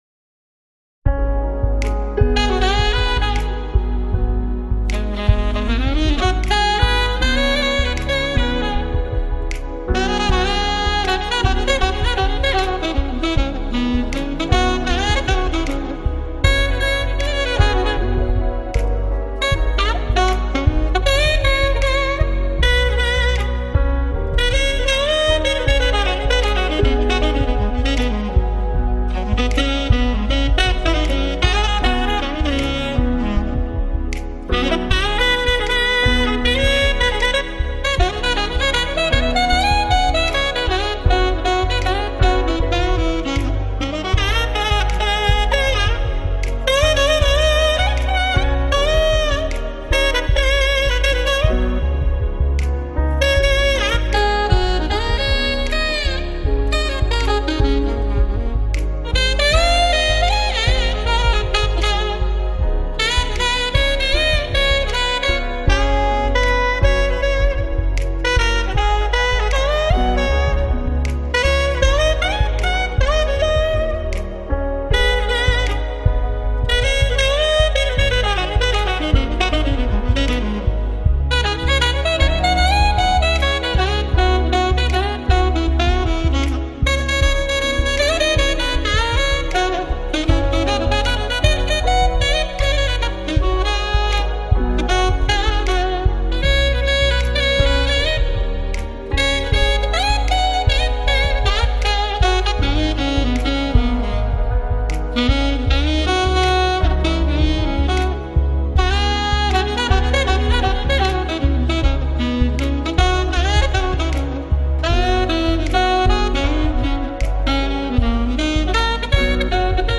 Жанр: Jazz